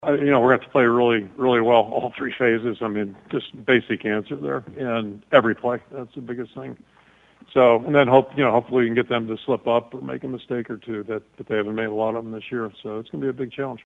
That’s Iowa coach Kirk Ferentz who says limiting turnovers is a team effort.